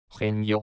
Hanno il suono di una "h" aspirata la consonante -g-, usata nei gruppi -ge, gi-, e la lettera -j- sempre.